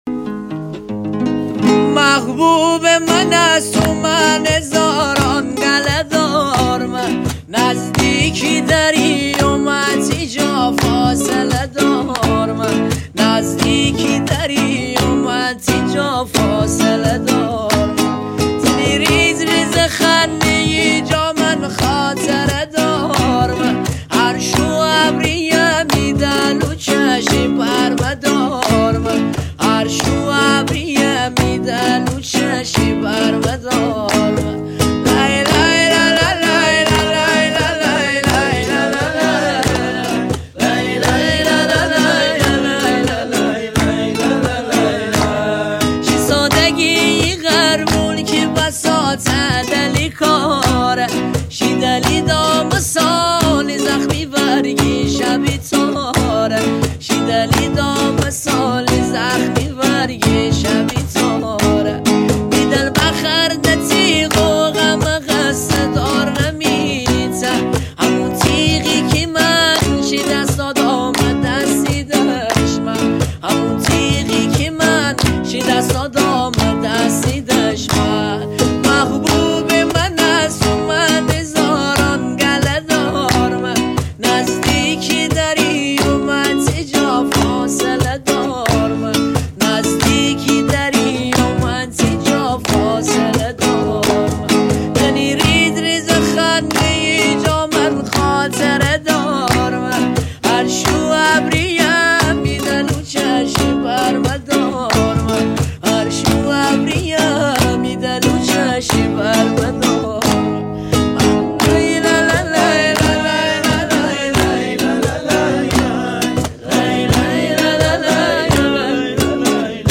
آهنگ مازندرانی
با گیتار